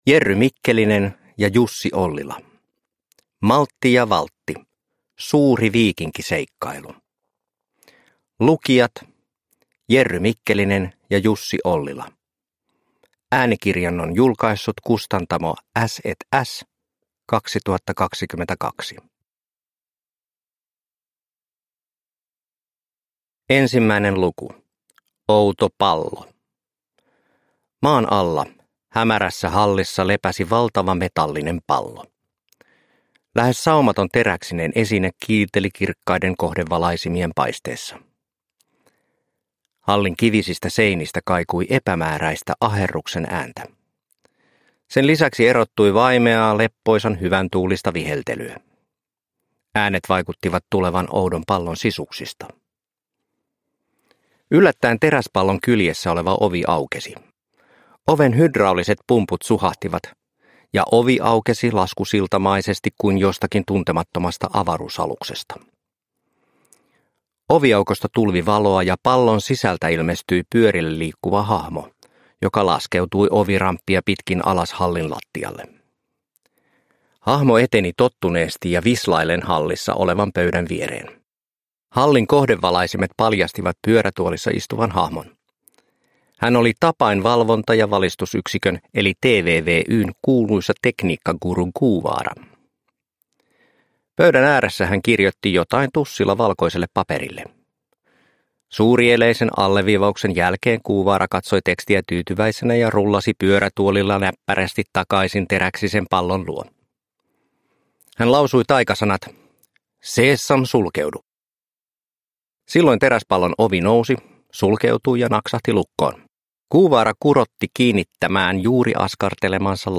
Maltti ja Valtti - Suuri viikinkiseikkailu – Ljudbok – Laddas ner